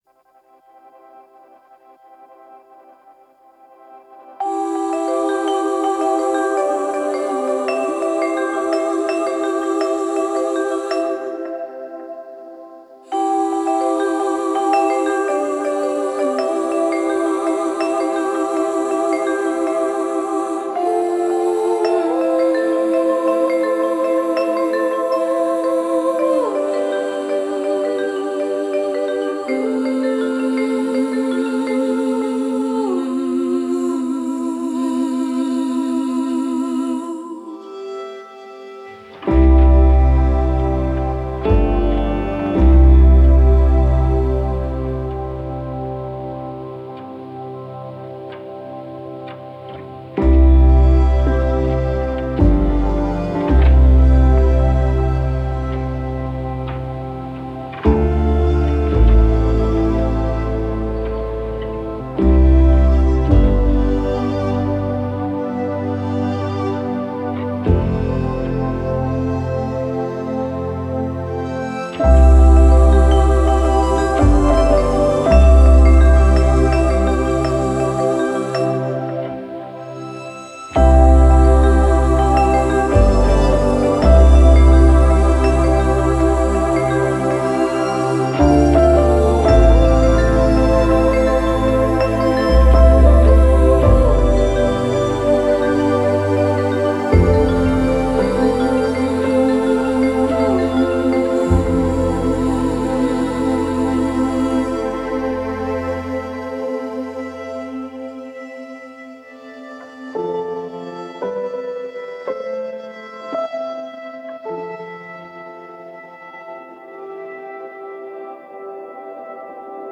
Delicate organic textures and emotional depth.